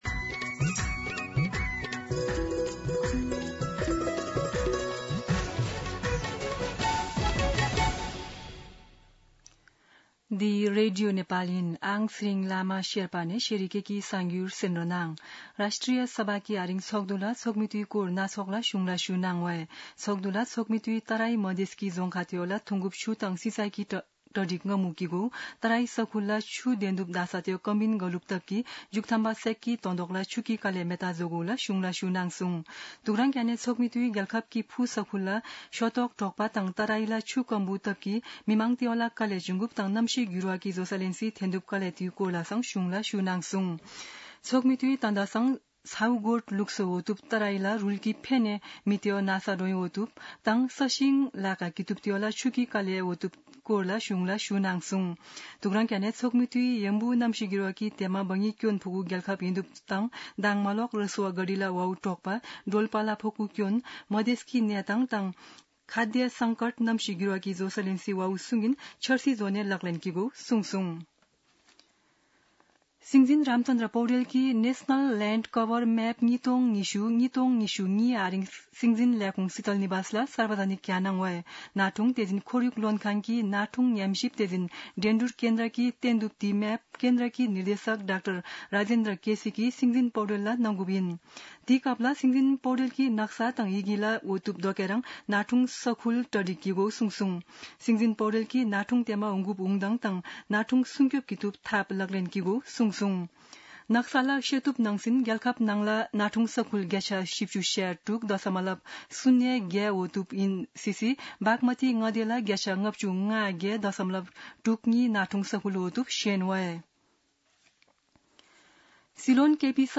शेर्पा भाषाको समाचार : ६ साउन , २०८२
Sherpa-News-06.mp3